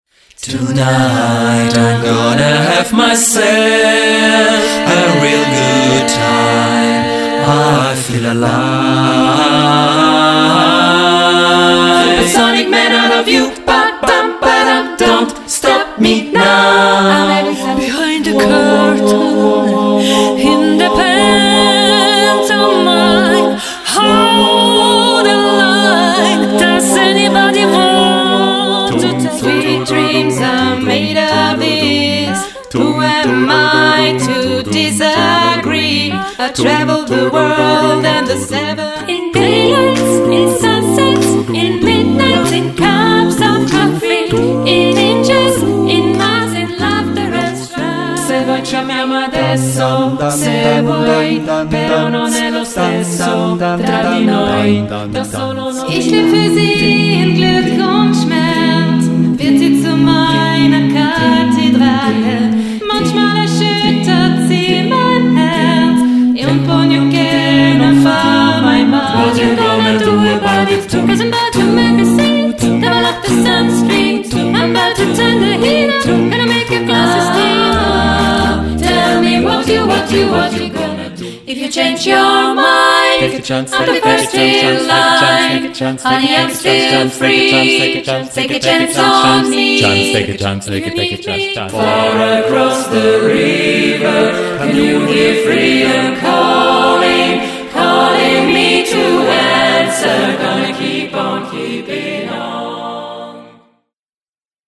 Wir sind eine 6-köpfige A-Cappella-Band aus Langenthal, die seit rund drei Jahren gemeinsam singt.
Pop, Rock, Jazz, Latino, Blues und Musical – immer a cappella und immer mit Herz.